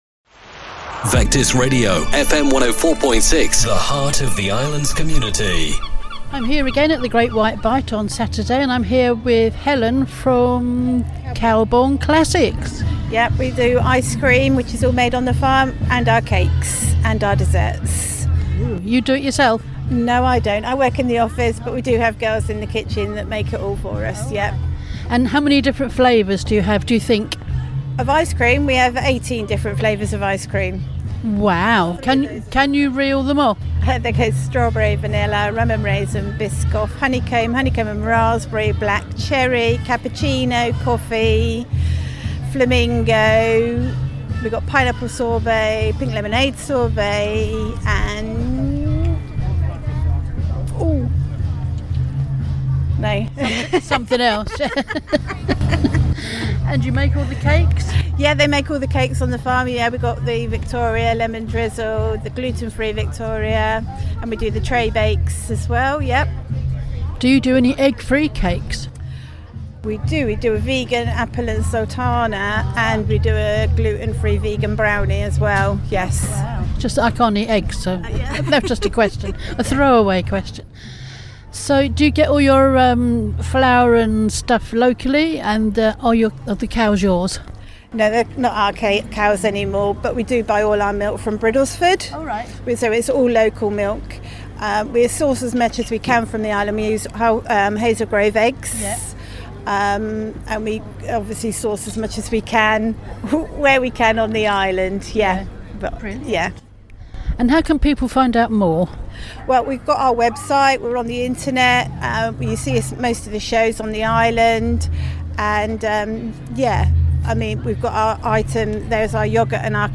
at the Great Wight Bite 2024.